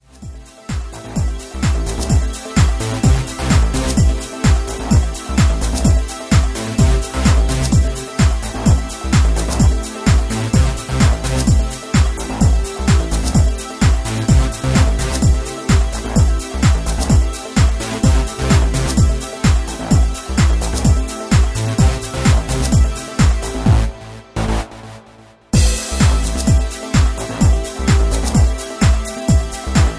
Backing track files: 2000s (3150)